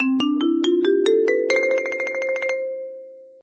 marimba_scale_up.ogg